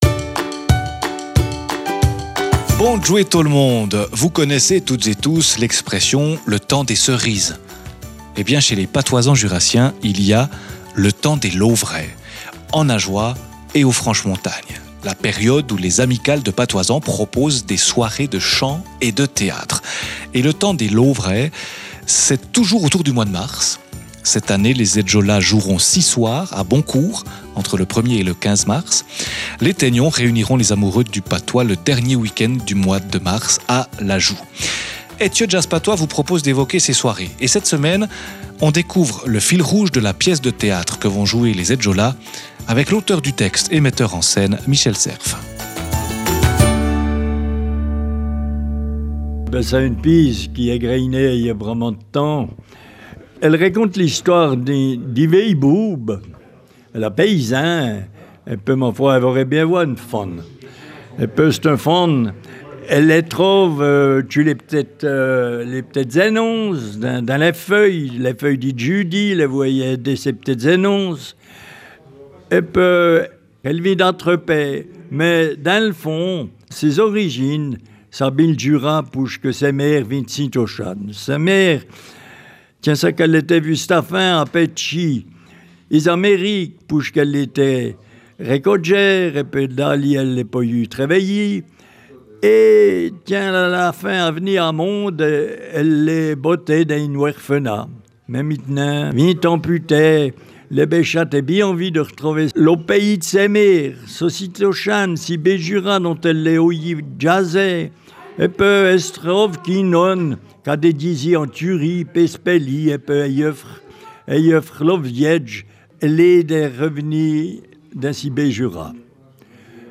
Djasans Patois Jurassien